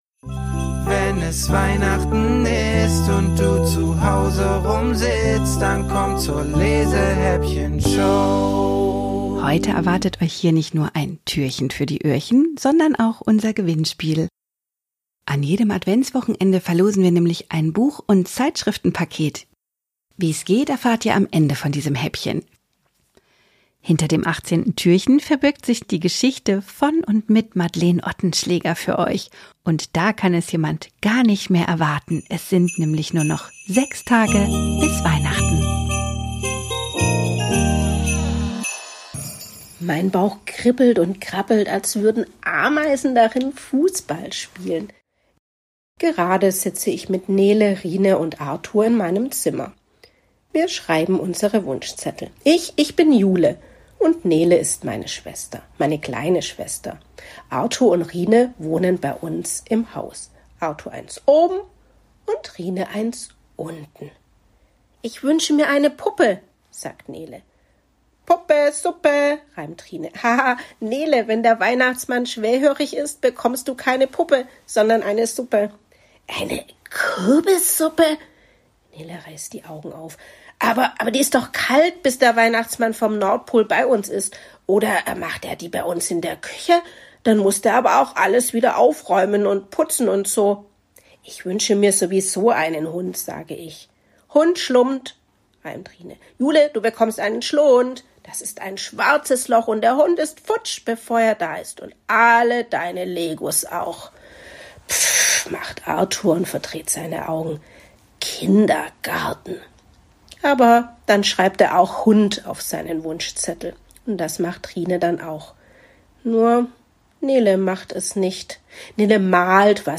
mit der LESEHÄPPCHEN-Show! 24 Autorinnen und Autoren versüßen Euch